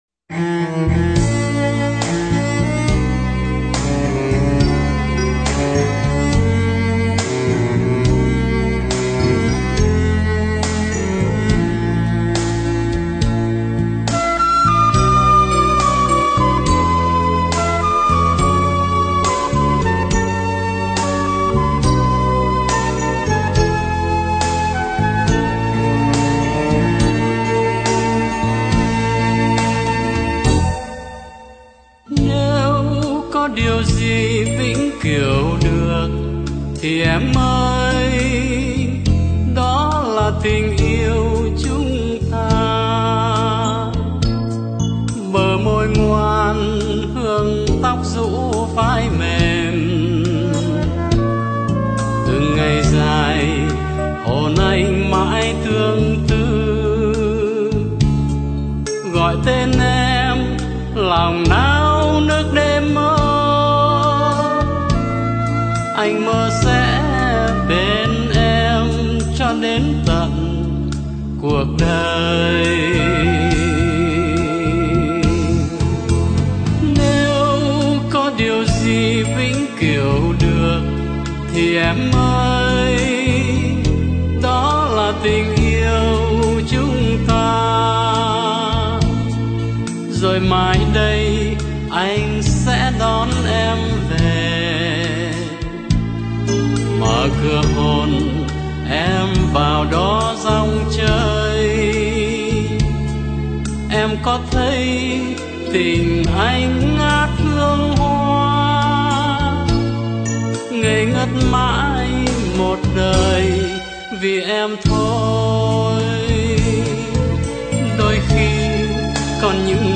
do chính tác giả hát.